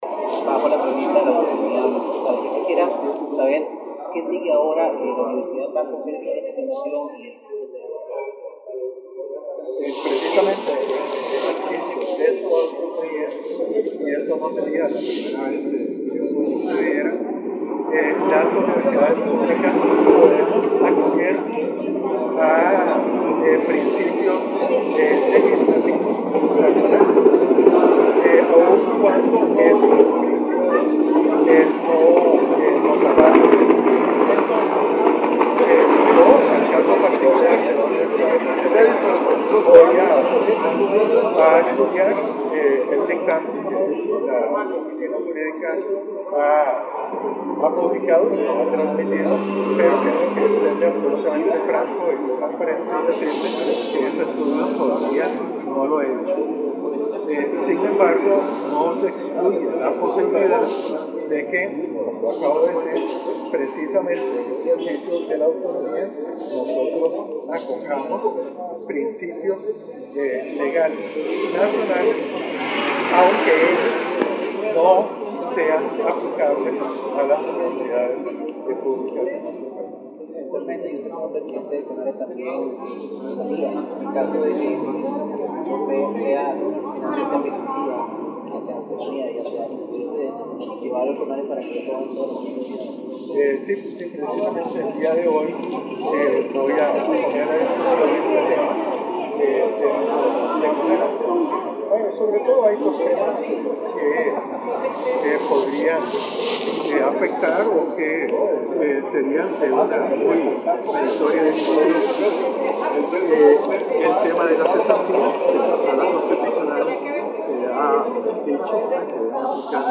Audio de la entrevista de Henning Jensen a CRHoy
entrevista-don-henning-crhoy.mp3